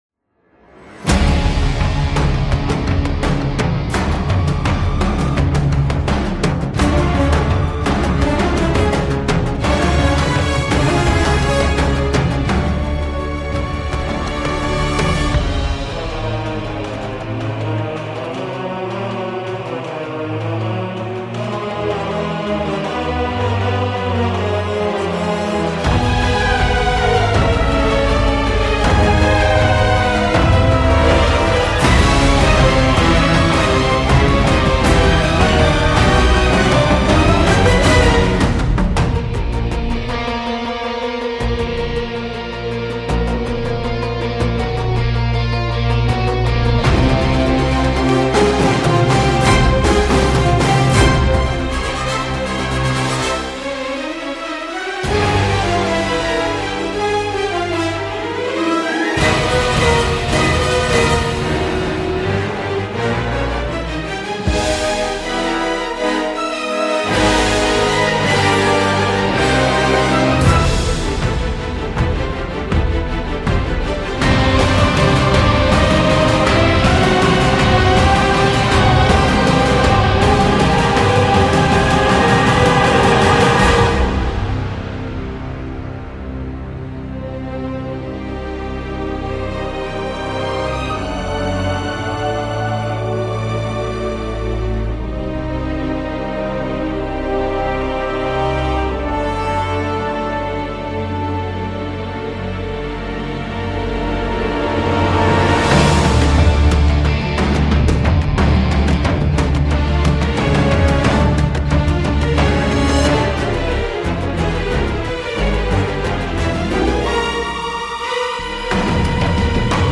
Les poncifs d’accords s’enchainent jusqu’à la nausée.